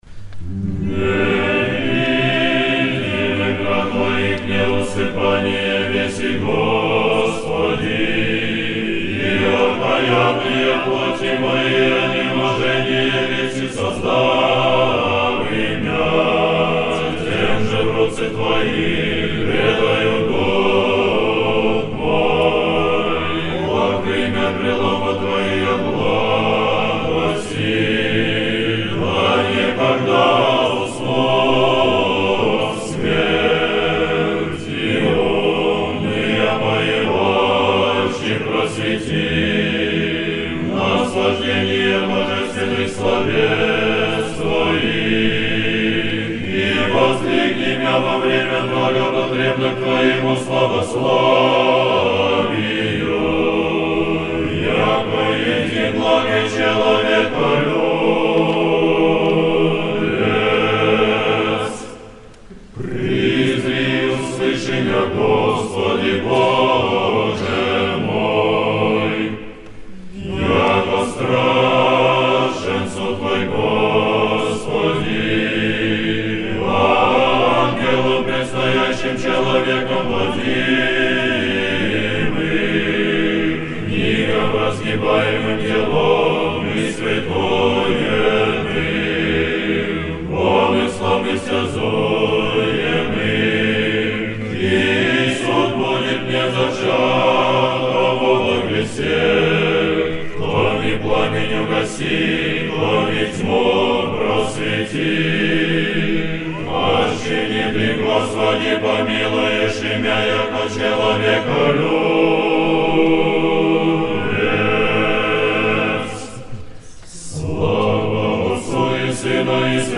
Великое повечерие. Хор Сретенского монастыря и хор Сретенской духовной семинарии. Запись сделана в монастырском соборе Сретения Владимирской иконы Божией Матери.
Тропари, глас 8